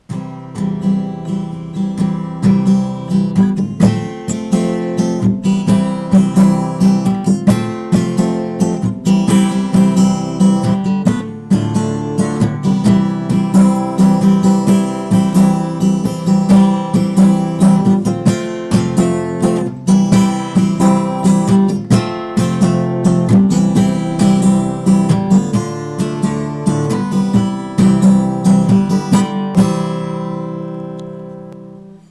Eigentlich wollte sie Schlagzeug lernen, aber ihre Mama war weniger begeistert und hat Gitarre vorgeschlagen.
spielt die Begleitung